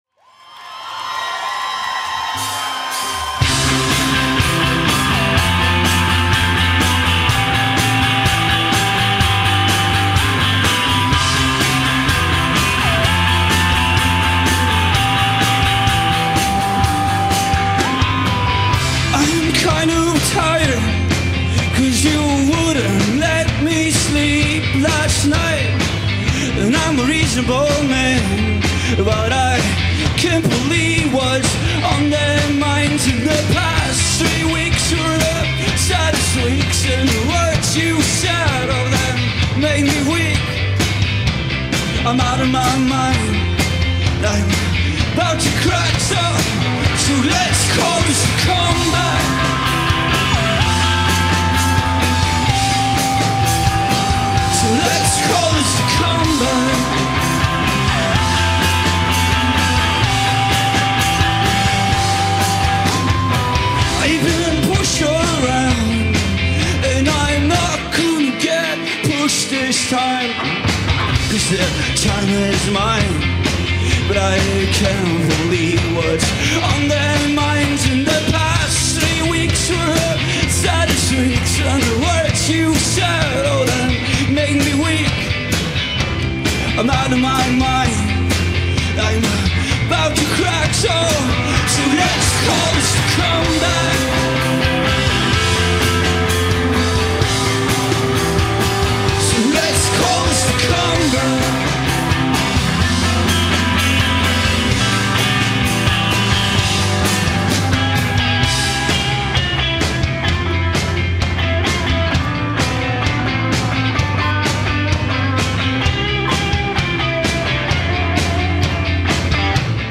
Haldern Pop Festival - West German Radio
peppy Swedish pop